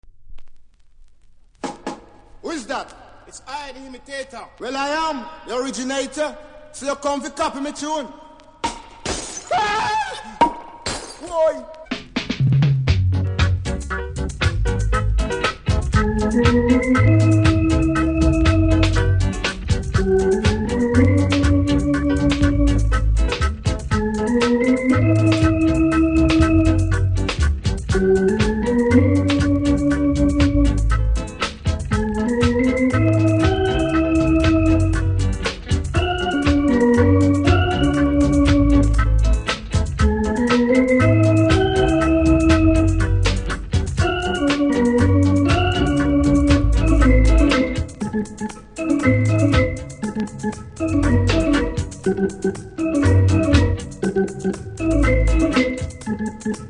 REGGAE 70'S
多少キズありますが音にはそれほど影響しておりませんので試聴で確認下さい。